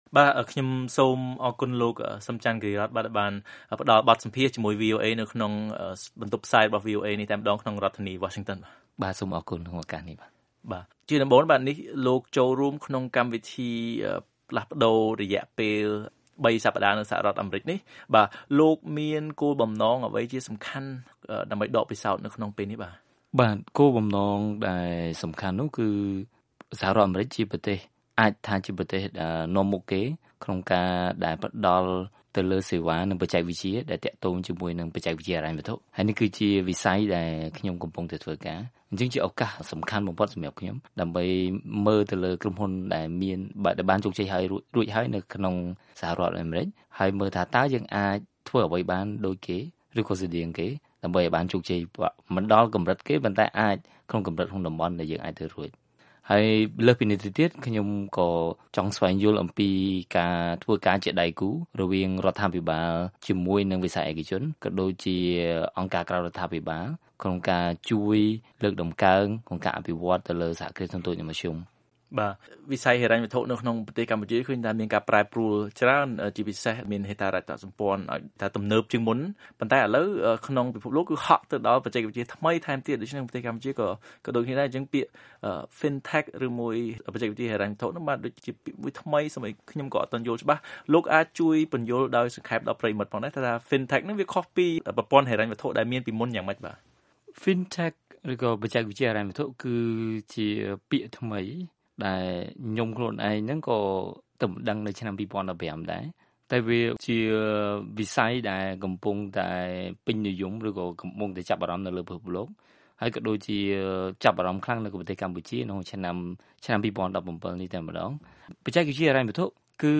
បទសម្ភាសន៍ VOA៖ បច្ចេកវិទ្យា fintech បង្កើនប្រសិទ្ធភាពនិងសក្តានុពលនៃវិស័យហិរញ្ញវត្ថុកម្ពុជា